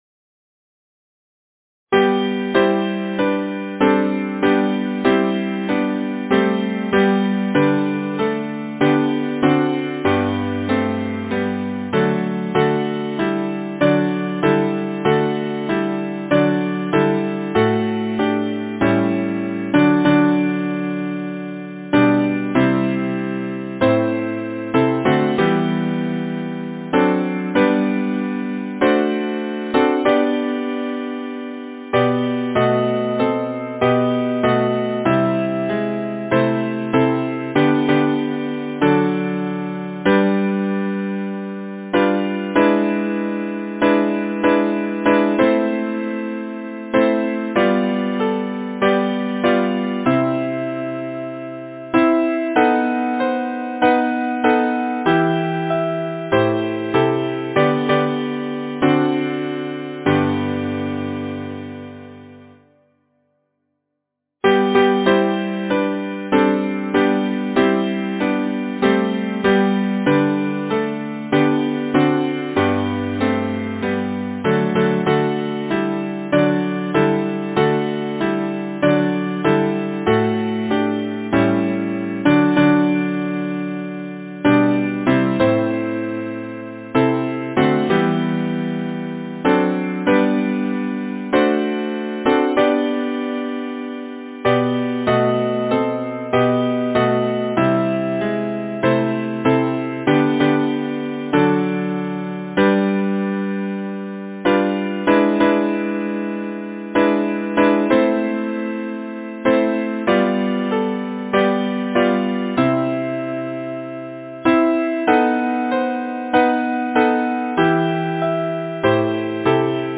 Title: Never say fail! Composer: Henry Lahee Lyricist: Aldine Silliman Kieffer Number of voices: 4vv Voicing: SATB Genre: Secular, Partsong
Language: English Instruments: A cappella